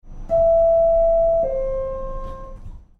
SubwayDoorWarning.wav